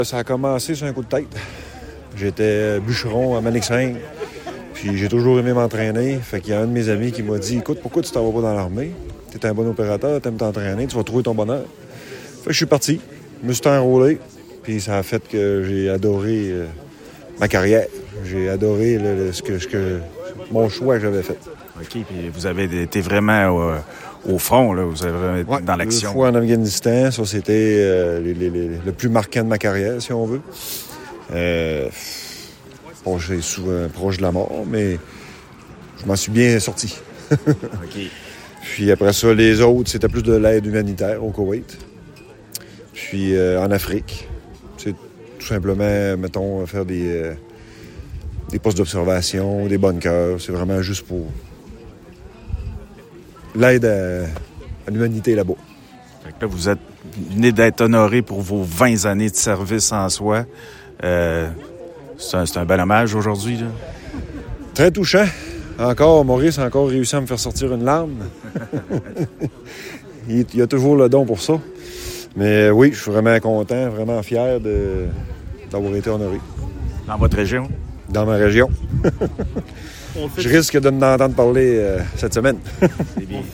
La municipalité de Saint-Siméon a commémoré le Jour du Souvenir aujourd'hui avec une cérémonie solennelle et émouvante tenue au cénotaphe de la halte de la rivière Noire.